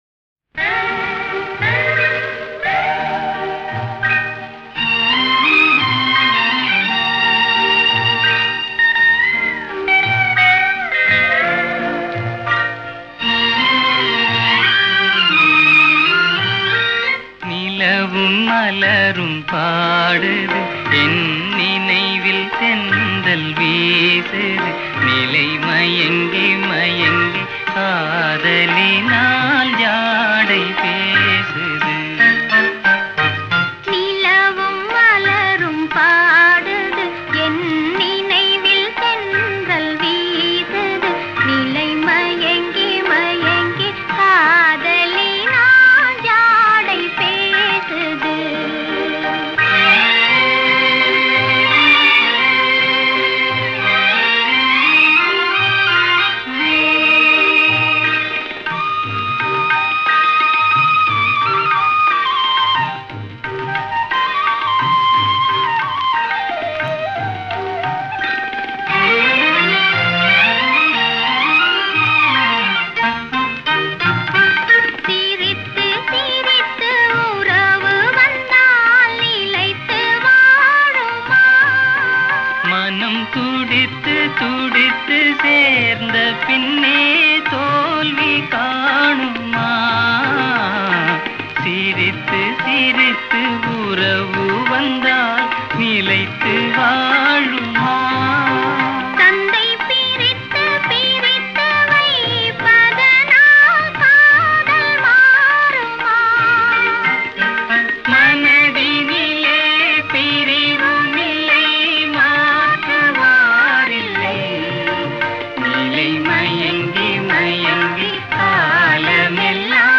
యుగళగీతం